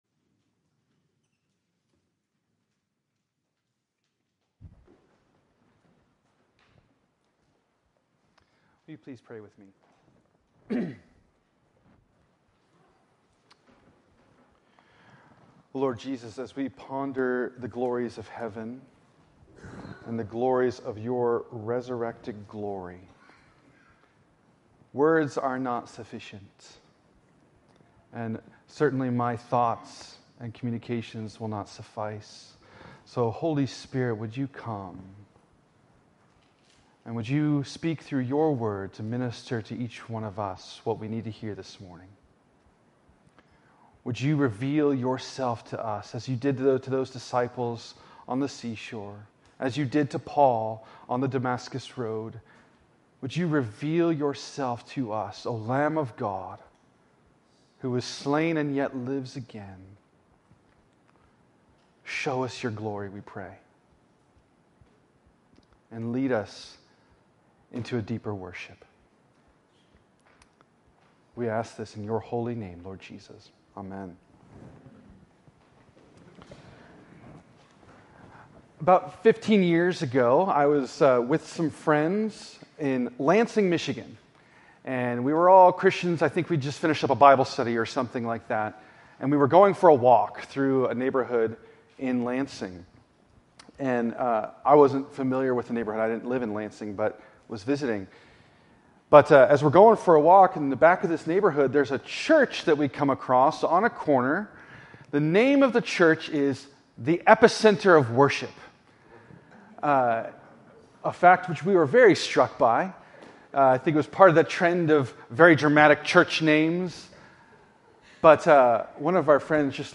Worship